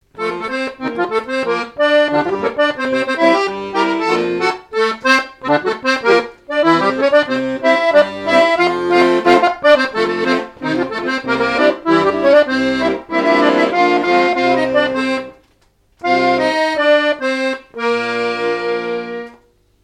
danse : scottich trois pas
airs de danses issus de groupes folkloriques locaux
Pièce musicale inédite